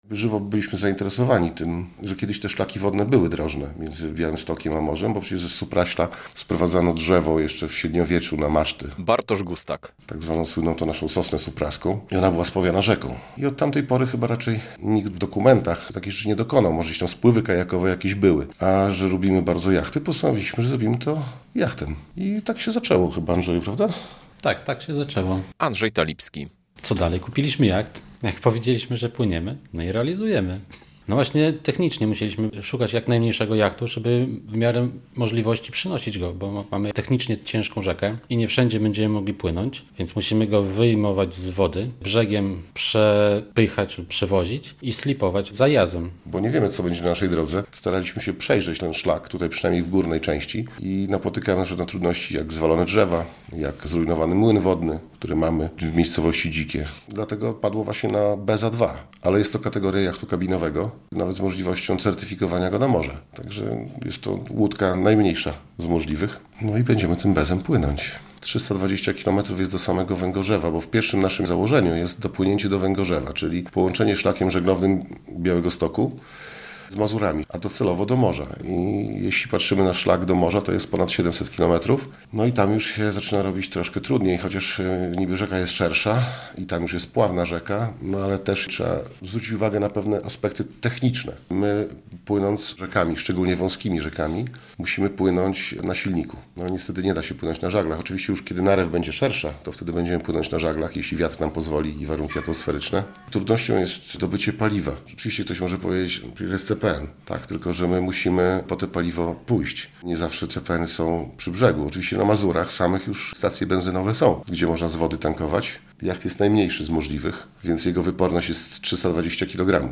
Dwóch przyjaciół-żeglarzy chce przepłynąć z Białegostoku do Bałtyku - relacja